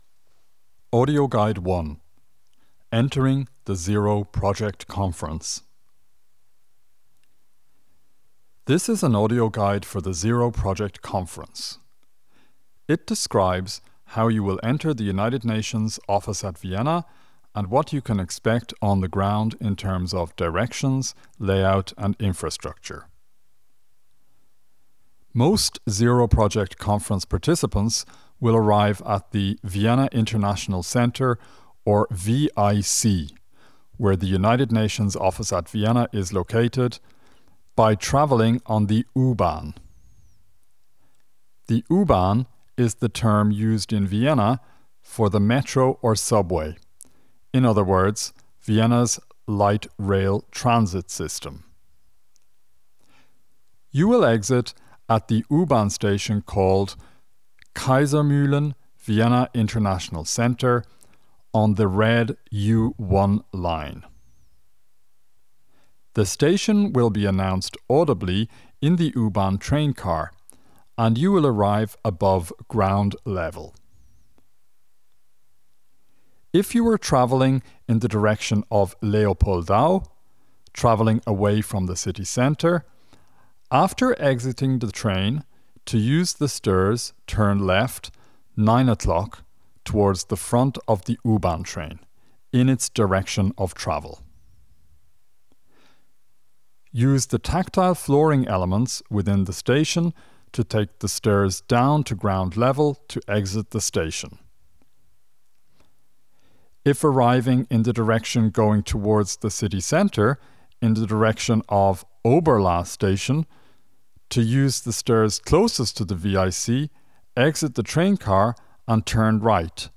Audio Guides for orientation